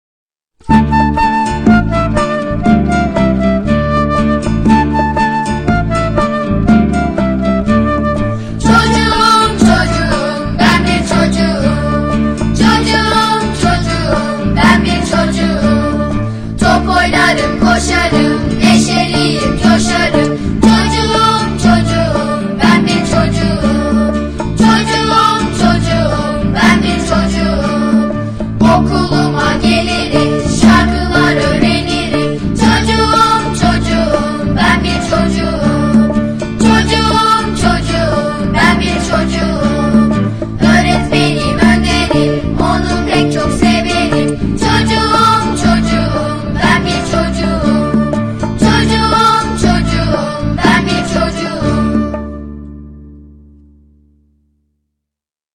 Çocuk Şarkıları